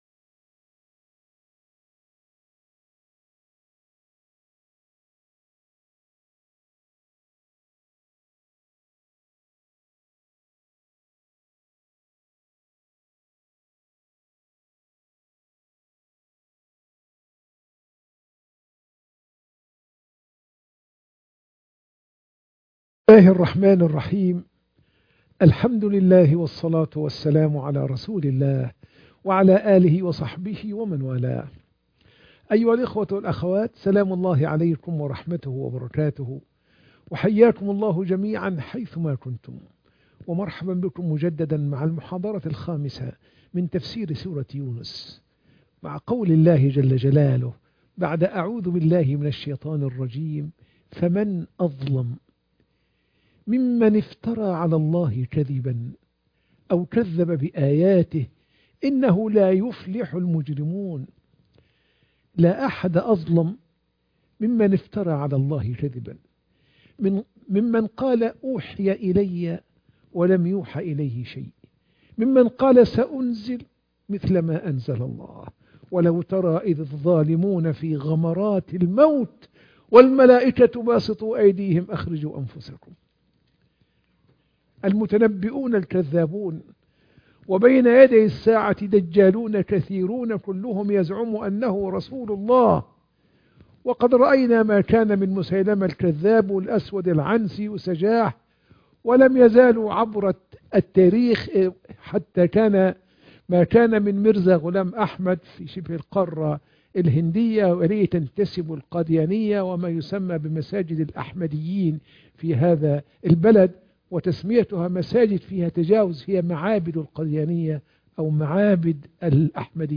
تفسير سورة يونس 11 - المحاضرة 5